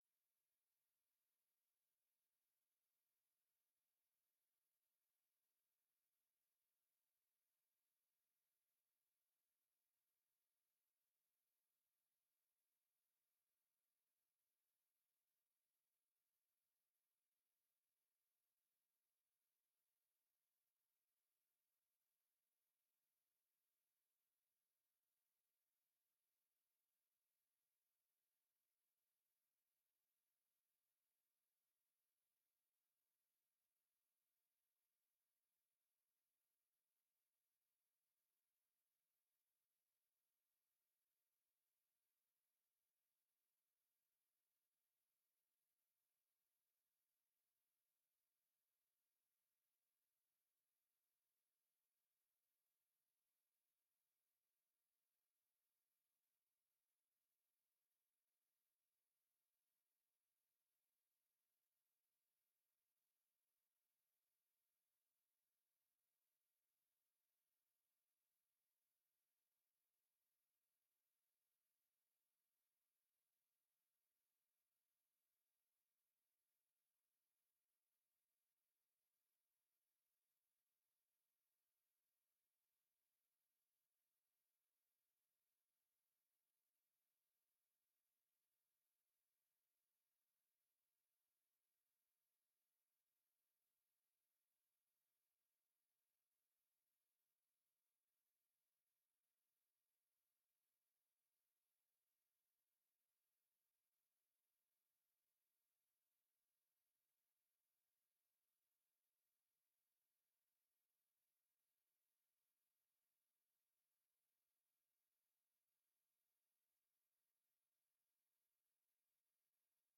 Raadsvergadering 14 september 2023 19:30:00, Gemeente Ouder-Amstel